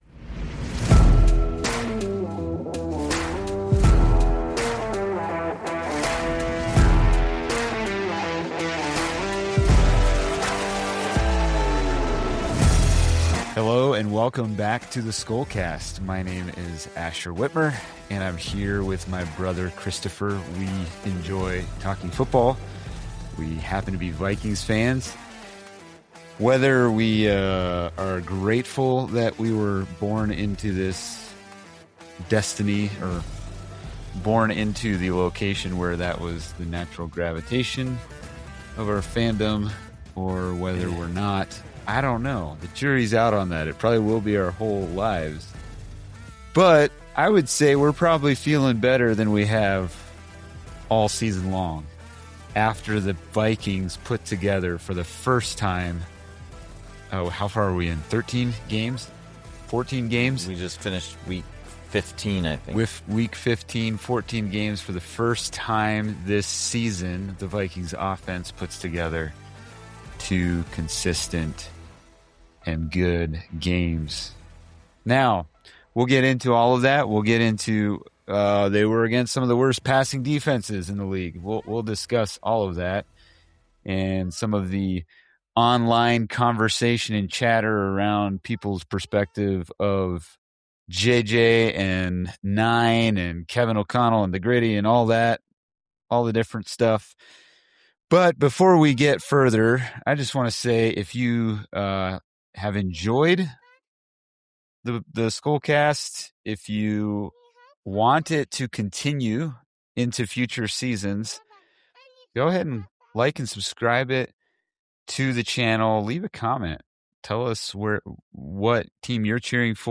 A grounded conversation about patience, progress, and perspective.